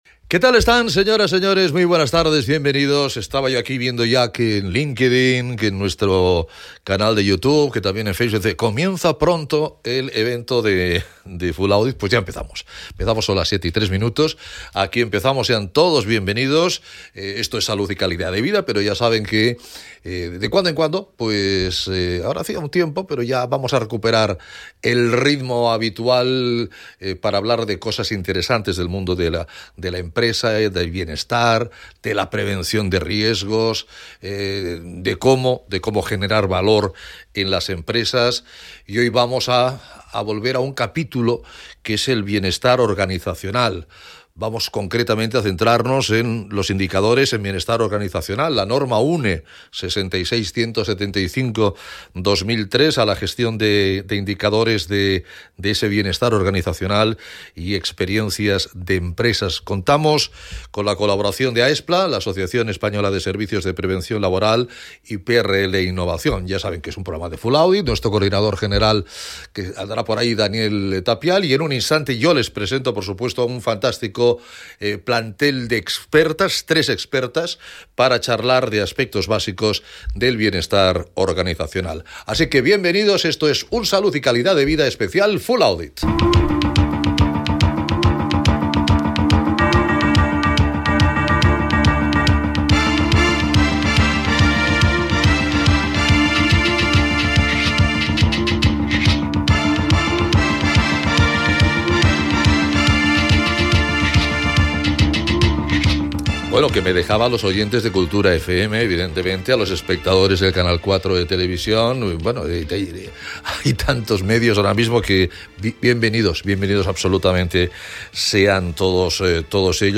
El programa se emitió en directo, tanto por radio para Barcelona y área metropolitana (92.8 FM), como por vídeo en Facebook Live con horario de 19:00 a 20:00